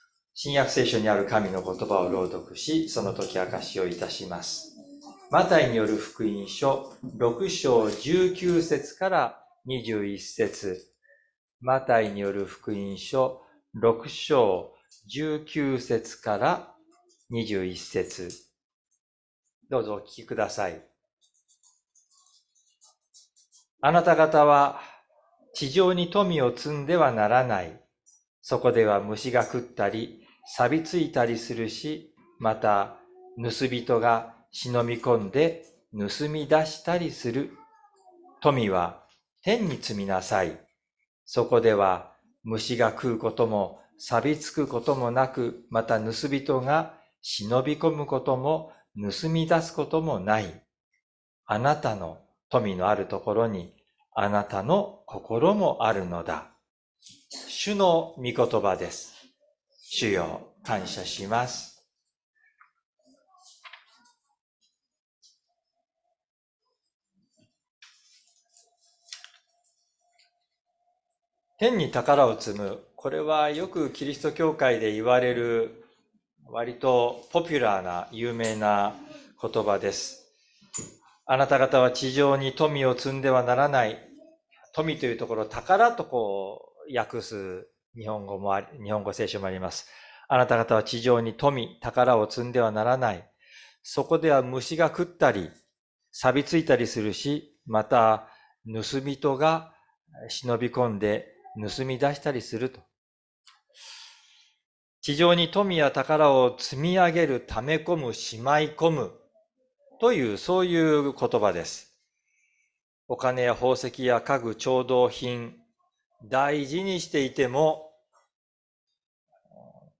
説教アーカイブ 日曜朝の礼拝 2024年02月11日「あなたの宝はどこにある?」
礼拝説教を録音した音声ファイルを公開しています。